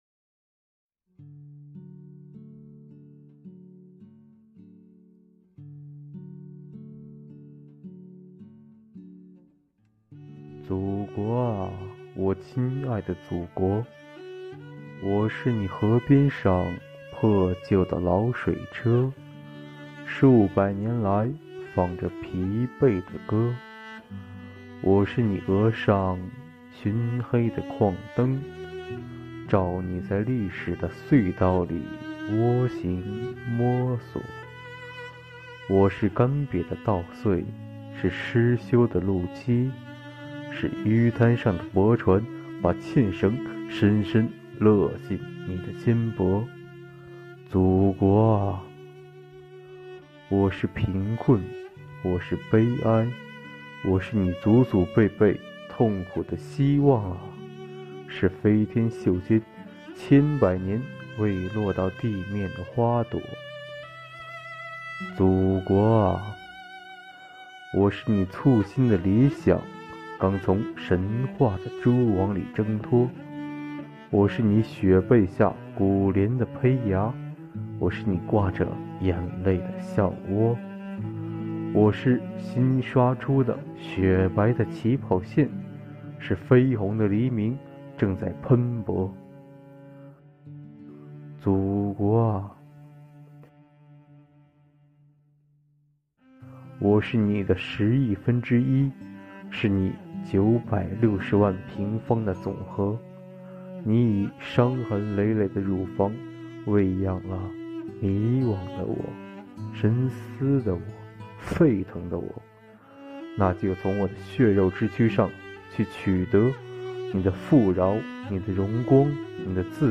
为隆重庆祝中华人民共和国成立71周年，加强青年思想道德教育，弘扬爱国主义精神，种子公司团支部组织全体青年开展“建国71周年诗朗诵”主题活动，本次活动自2020年9月25日开展至2020年10月23日结束。